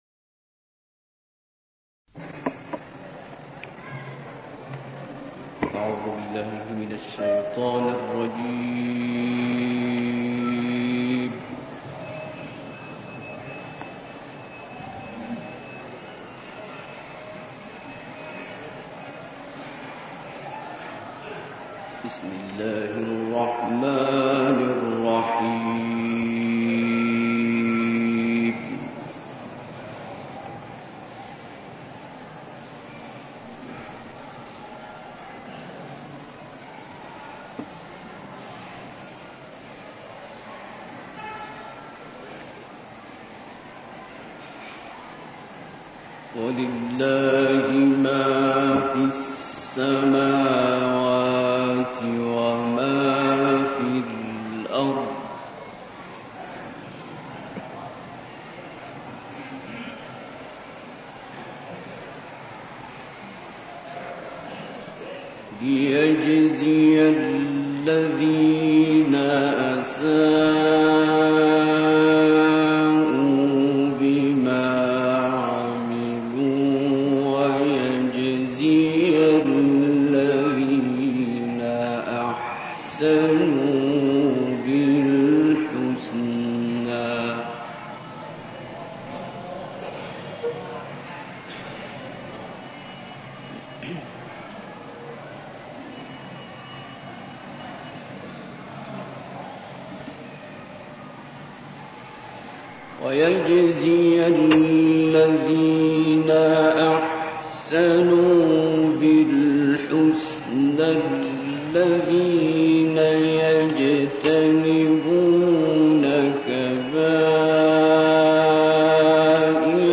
Lecture du Coran par Abdul Bassit à la mosquée Chafii au Caire
Nous vous proposons la lecture de quelques versets des saintes sourates Najm et Qamar, par le grand lecteur coranique égyptien, Abdul Bassit Mohammad Abdos Samad.